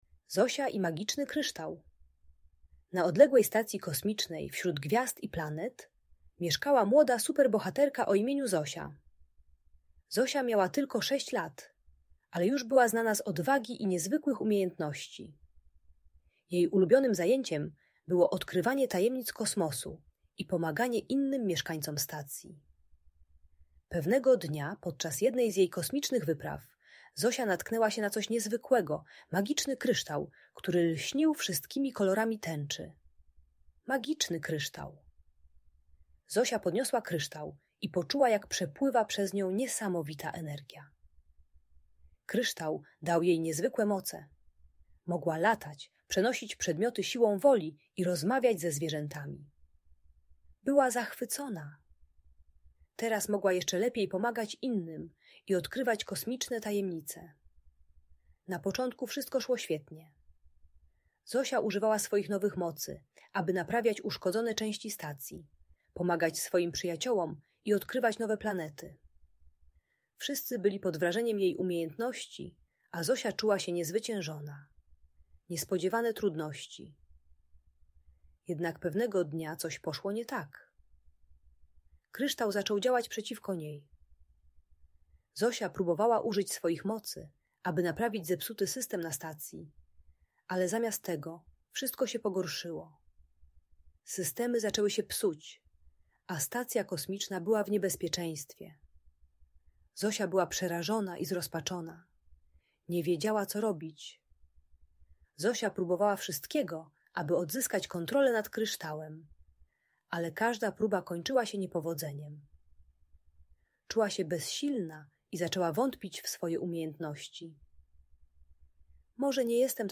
Zosia i Magiczny Kryształ - Opowieść o Odwadze i Przyjaźni - Audiobajka dla dzieci